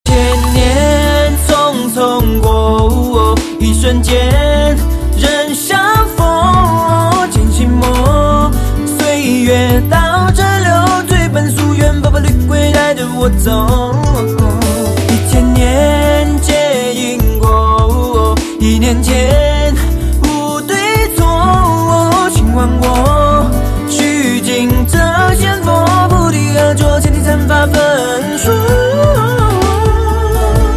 M4R铃声, MP3铃声, 华语歌曲 110 首发日期：2018-05-16 01:28 星期三